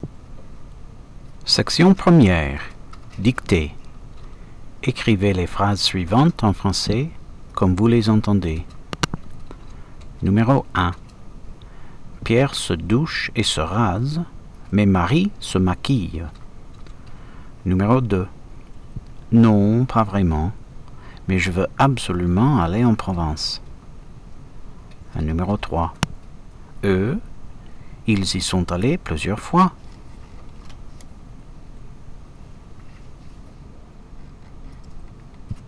Dictée*